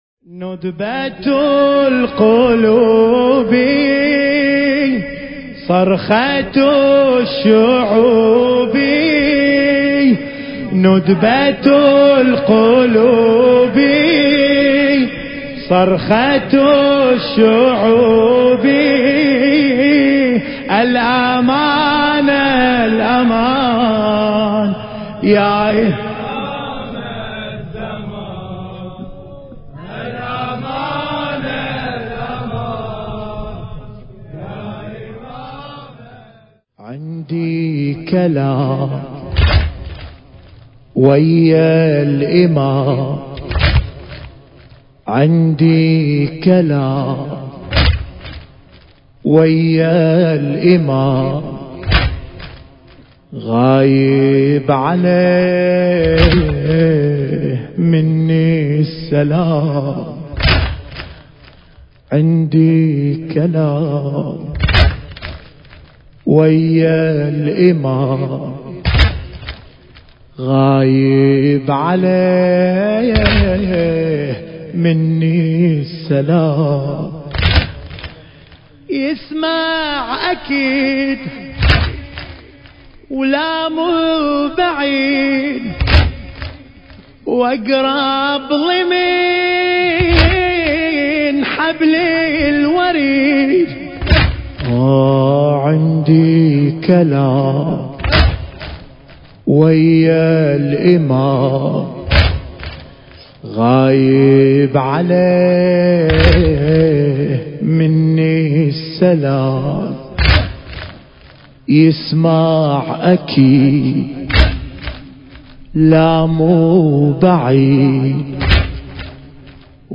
في ذكرى شهادة الامام الحسن العسكري (عليه السلام) بقرية صدد ١٤٣٧ هـ